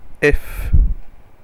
Ääntäminen
Synonyymit phénylalanine Ääntäminen France - Paris Haettu sana löytyi näillä lähdekielillä: ranska Käännöksiä ei löytynyt valitulle kohdekielelle.